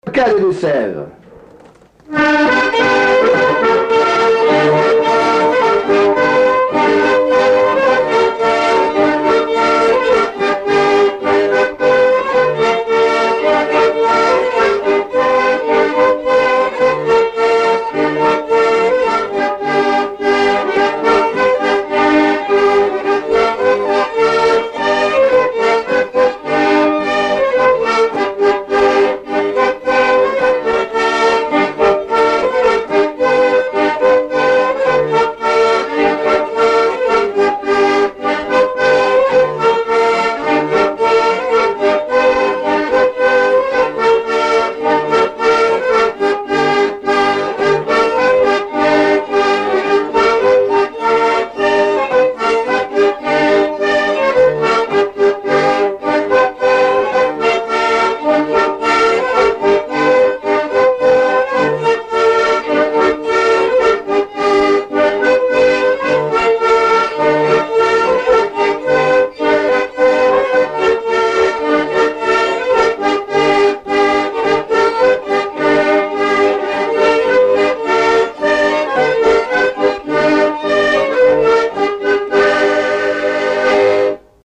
danse : polka
Pièce musicale inédite